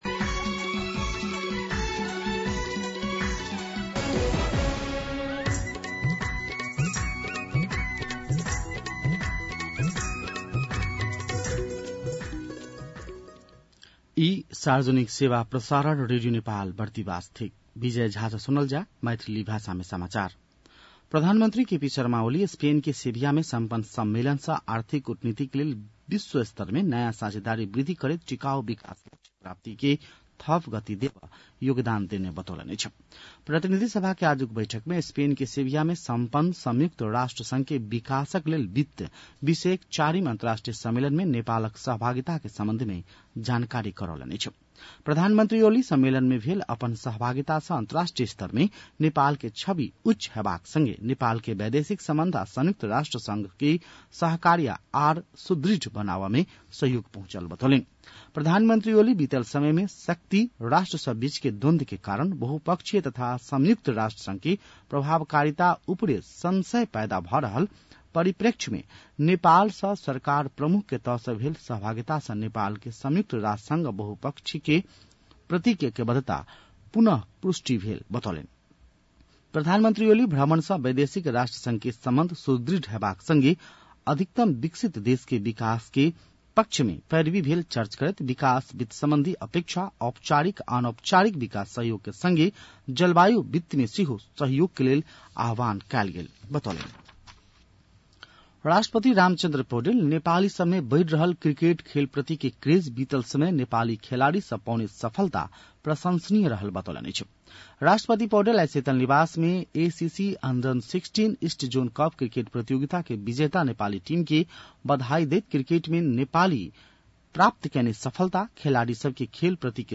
मैथिली भाषामा समाचार : २३ असार , २०८२
6.-pm-maithali-news-1-1.mp3